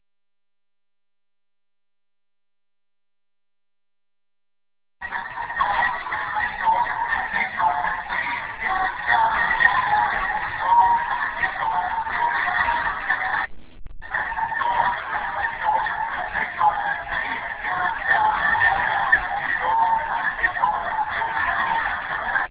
Once the video images were completed, we also had to assemble the sound effects for the corresponding video images into a .wav audio file.